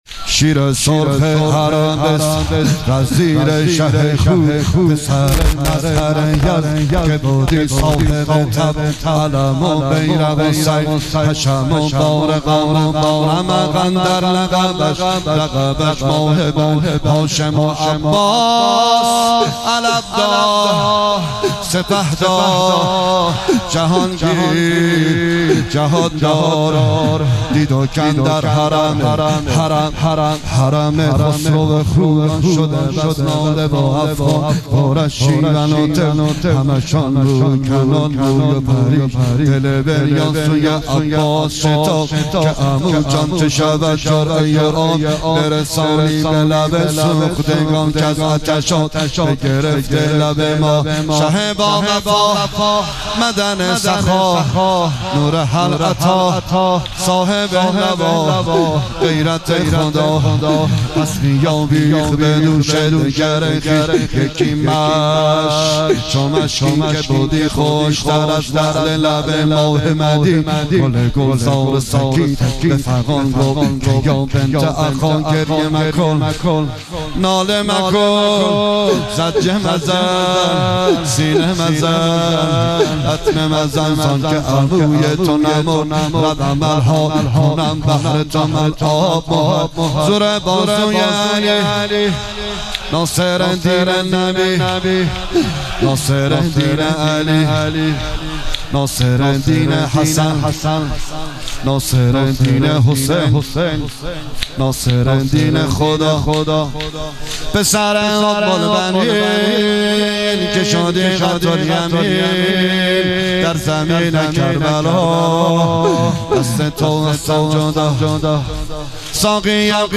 شب هفتم رمضان95
زمینه، روضه، مناجات